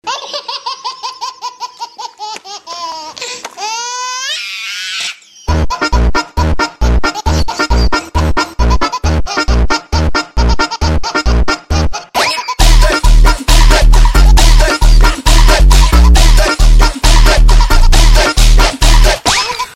Baby laugh jersey funk